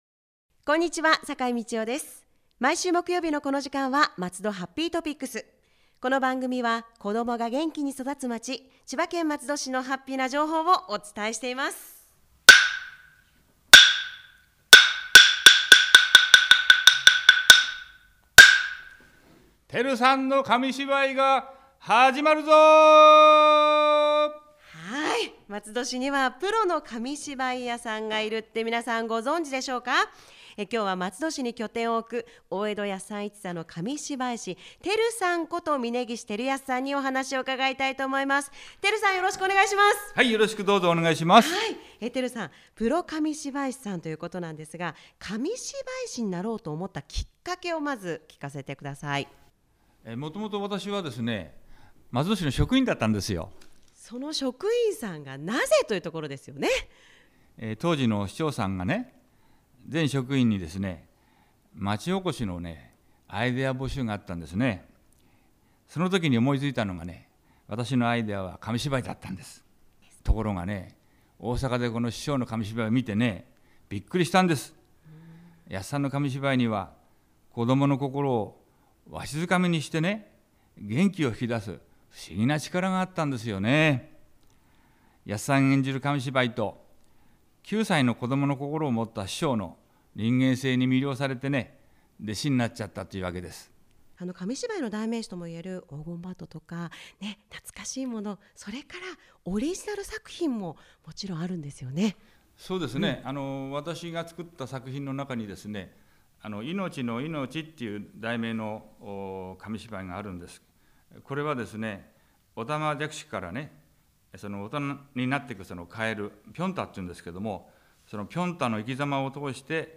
FMラジオ番組「MATSUDO HAPPY☆topics」｜松戸市
※著作権の関係により、バックミュージックなどの音楽を削除しています。